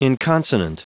Prononciation du mot inconsonant en anglais (fichier audio)
Prononciation du mot : inconsonant